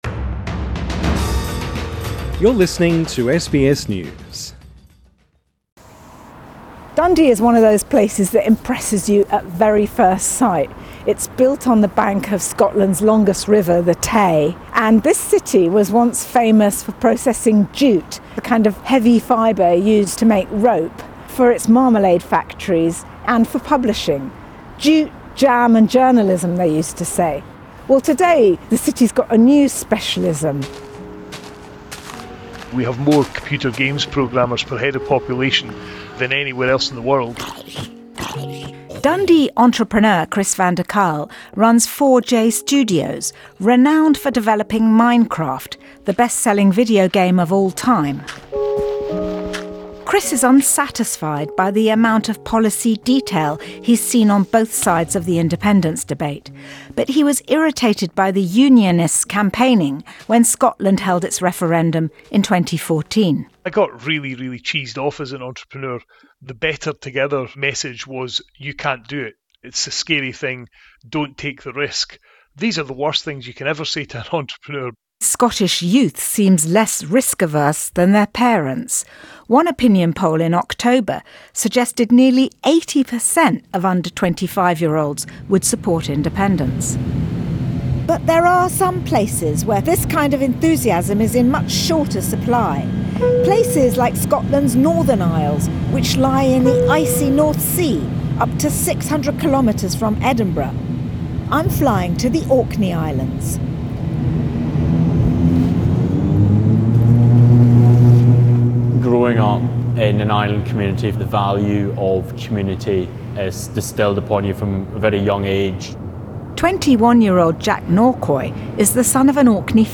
This story was first broadcast on the BBC World Service